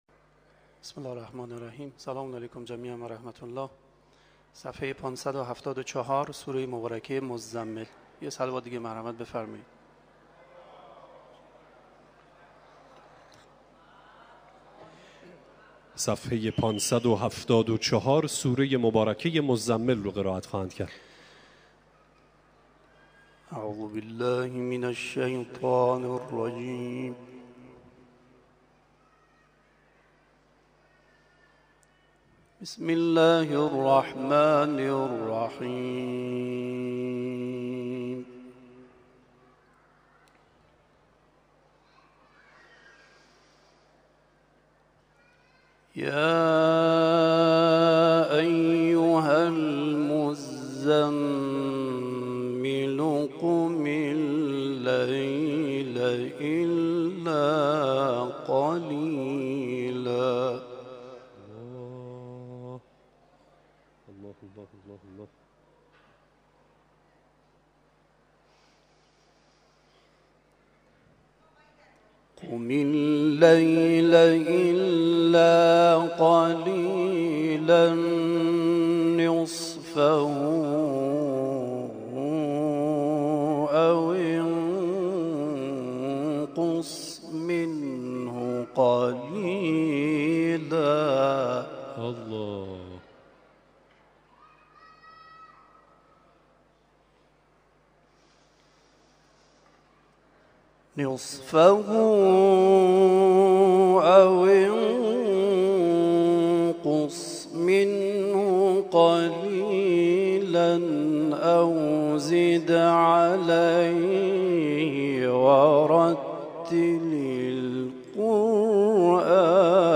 گروه جلسات و محافل: محفل انس با قرآن این هفته آستان عبدالعظیم الحسنی(ع) با تلاوت قاریان ممتاز و بین‌المللی کشورمان برگزار شد.